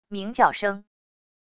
鸣叫声wav音频